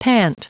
Prononciation du mot pant en anglais (fichier audio)
Prononciation du mot : pant